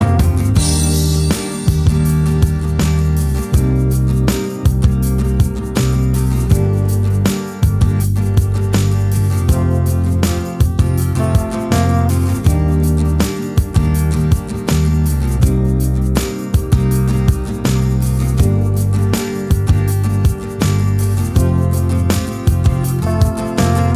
No Lead Guitar Indie / Alternative 4:33 Buy £1.50